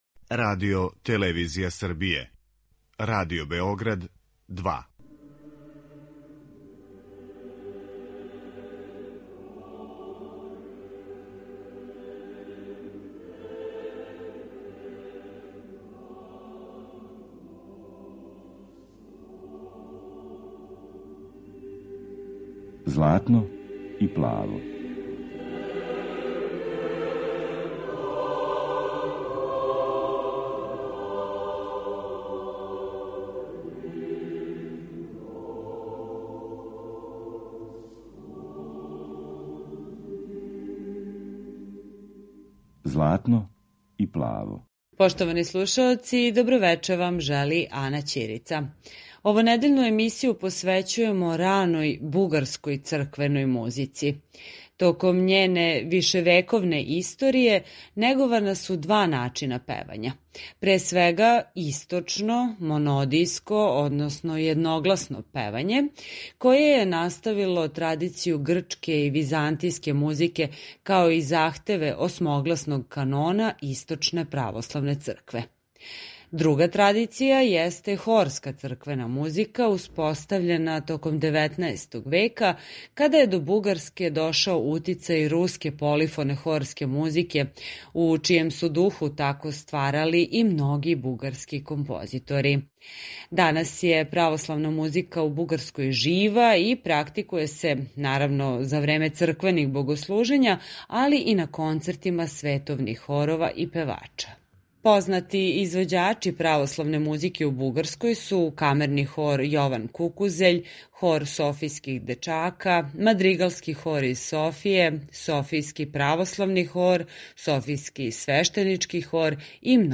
Бугарска духовна музика
Емитујемо примере раног једногласног појања уз исон, као и композиције које потписују Добри Христов и Петер Динев.